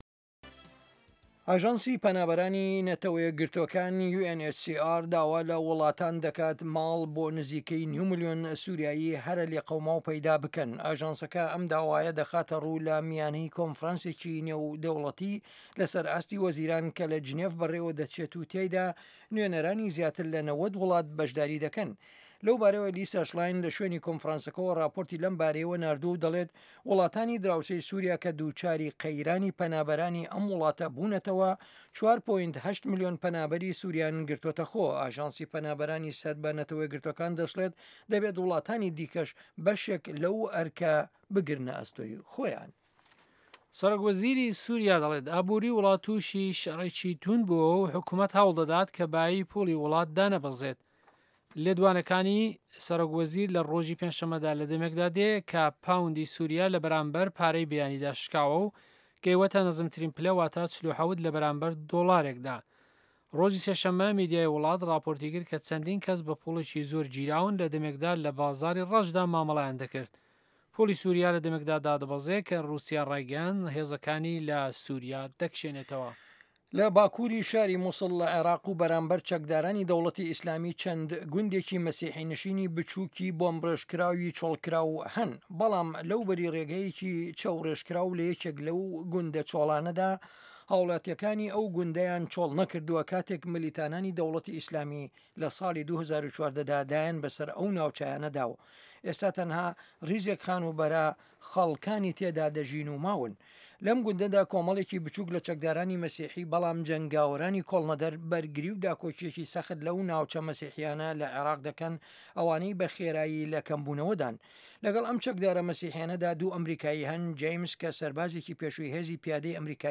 هه‌واڵه‌کان، ڕاپـۆرت، وتووێژ و پاشان سه‌رگوتاری ڕۆژنامه‌ که‌ تیایدا ڕاوبۆچوونی حکومه‌تی ئه‌مه‌ریکا ده‌خرێته‌ ڕوو.